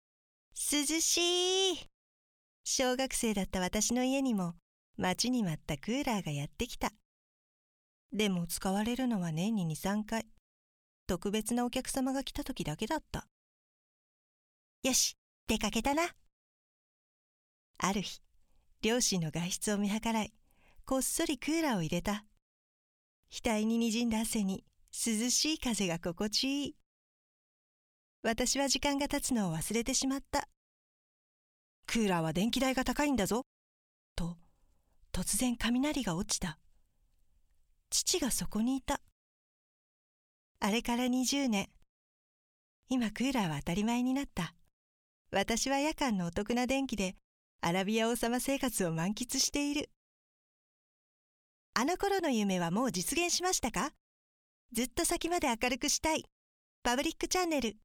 ボイスサンプル → |  sample 1 |  sample 2 |